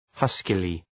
Προφορά
{‘hʌskılı}